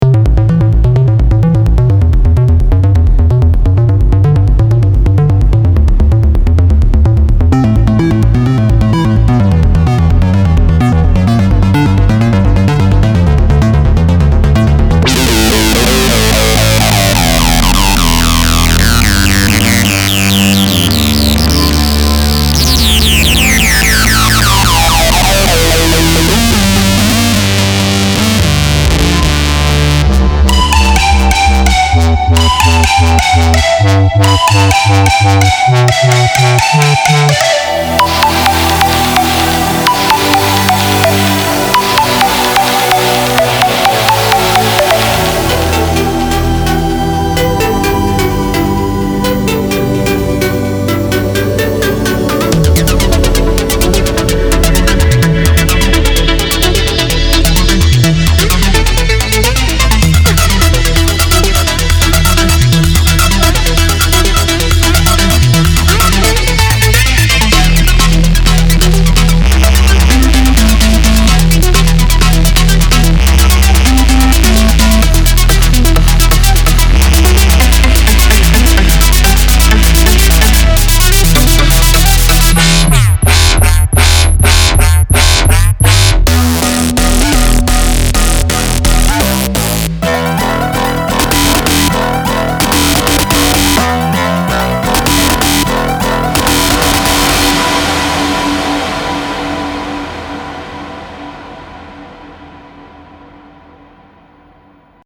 在此包中，您将找到从头开始构建的64个原始合成器预设。
并查看“ BA State of EDM 2015”，这是一个震颤的低音，但听起来像是高调的费雪笛子。